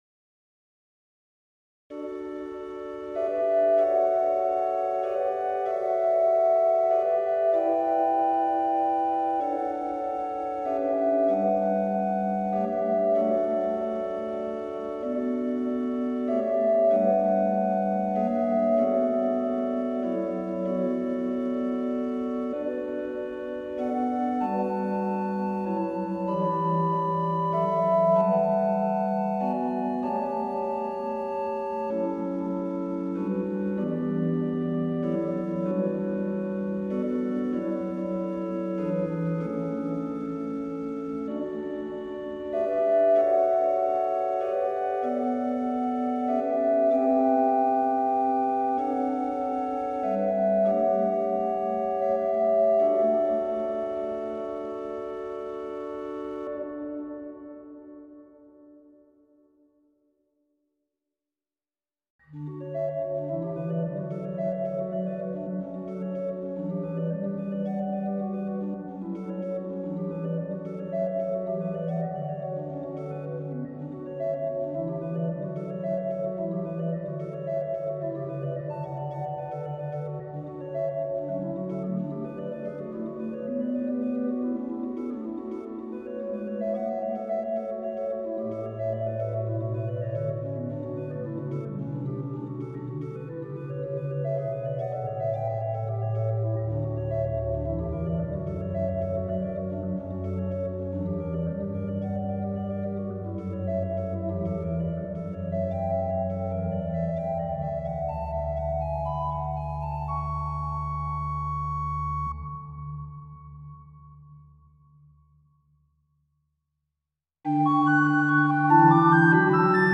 Keyboards Variations
I - En estilo de coral
VII - En estilo de coral, con un trino en pedal
X - Con el canto abajo y la armonía ambigua.